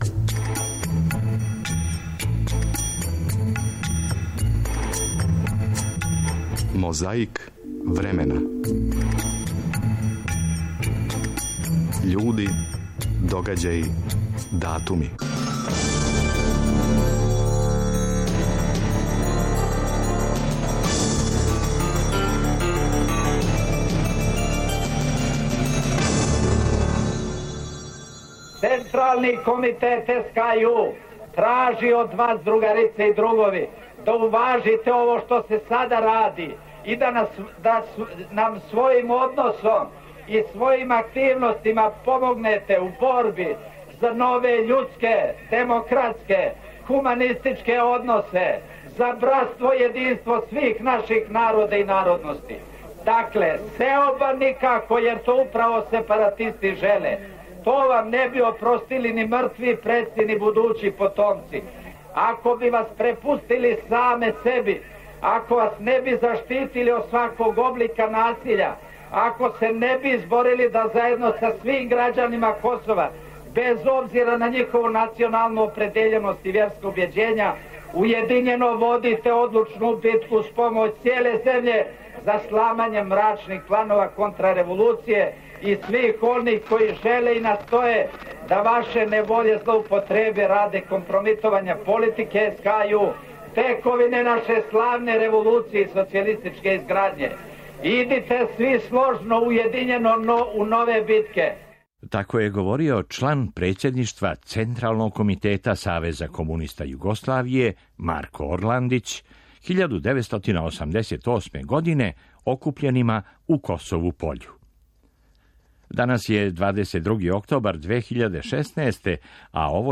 Песникиња је говорила на уручењу али прво генерал Петар Грачанин.
Подсећа на прошлост (културну, историјску, политичку, спортску и сваку другу) уз помоћ материјала из Тонског архива, Документације и библиотеке Радио Београда.